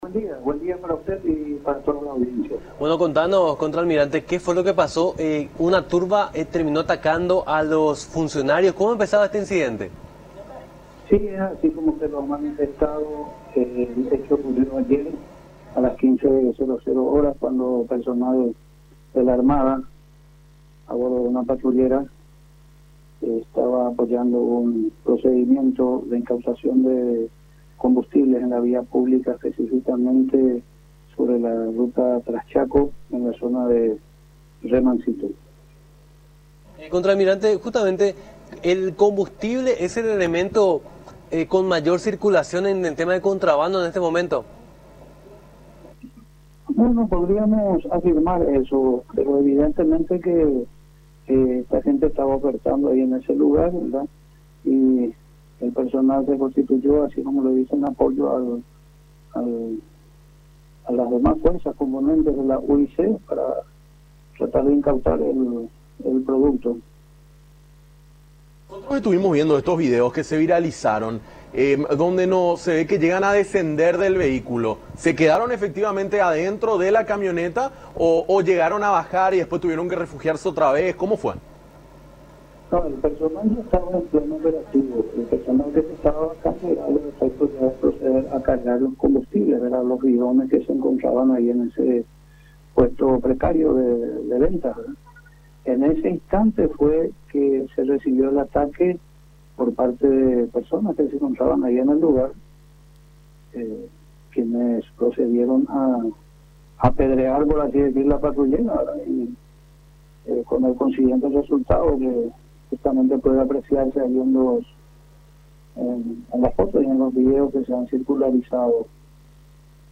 “Personal de la Armada estaba acompañando a la UIC (Unidad Interinstitucional Anticontrabando) durante un procedimiento de incautación de combustibles (…) Fue allí que, cuando llegaban al lugar, fueron recibidos con lanzamientos de piedras. Apedrearon la patrullera”, dijo el contraalmirante Luis Ciancio, prefecto general naval de la Armada Paraguaya, en diálogo con Nuestra Mañana a través de Unión TV, en alusión a las personas que estarían implicadas en la venta ilegal de carburantes.